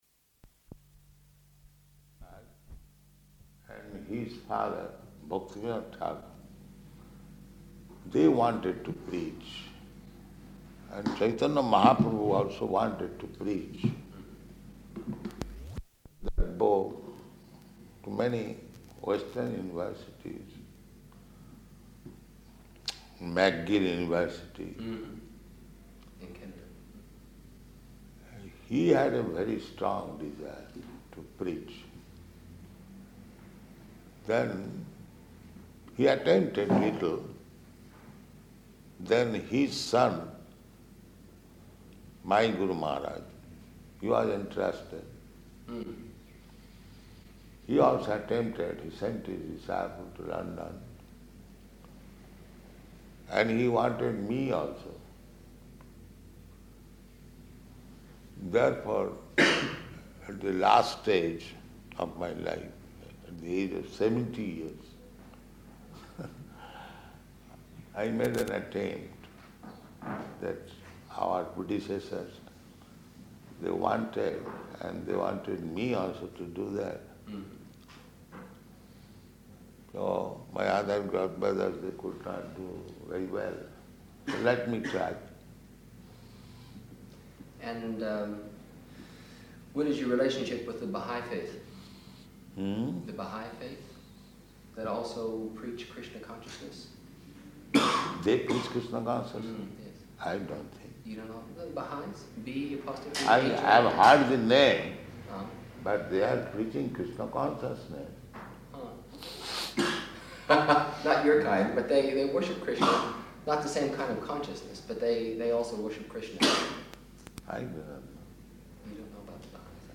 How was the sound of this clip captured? Location: Melbourne